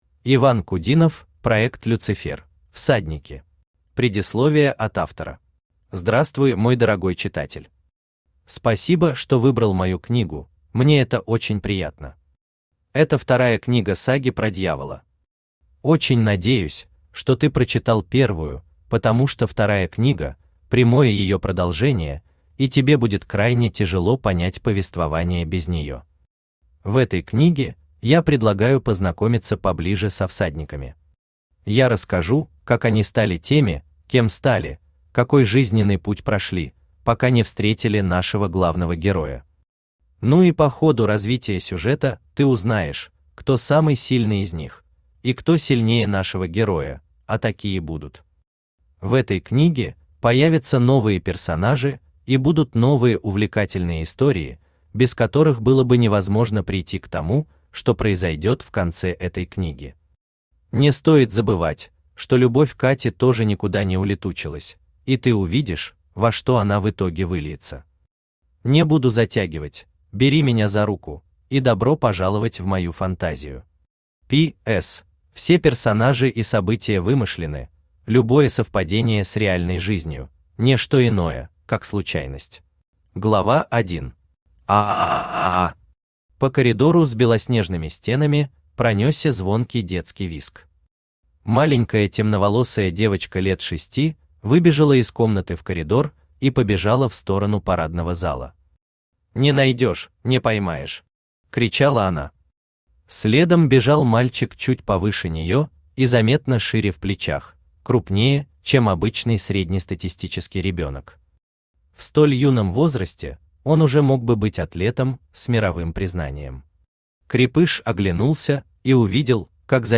Аудиокнига Проект «Люцифер»: Всадники | Библиотека аудиокниг
Aудиокнига Проект «Люцифер»: Всадники Автор Иван Сергеевич Кудинов Читает аудиокнигу Авточтец ЛитРес.